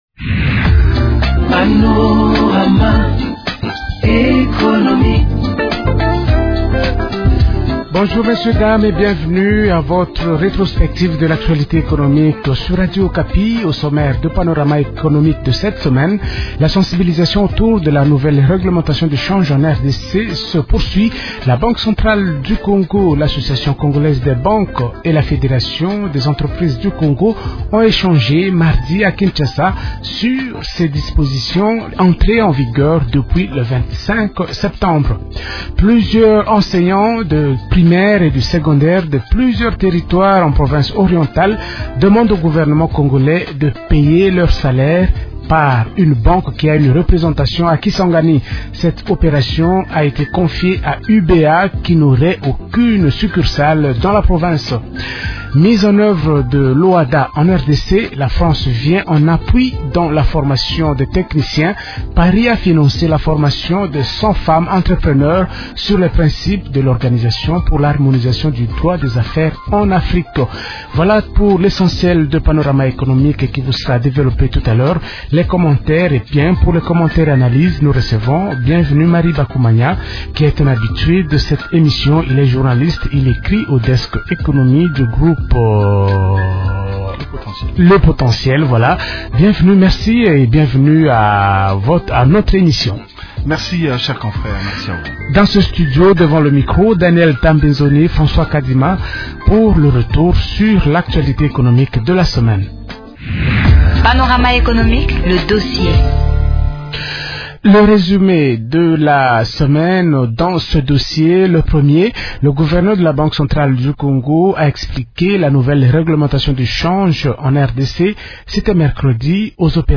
A l’occasion, le gouverneur de la BCC, Déogratias Mutombo, est revenu sur les dispositions spécifiques relatives à la nouvelle règlementation dont la primauté dh franc congolais dans toutes les transactions commerciales en RDC.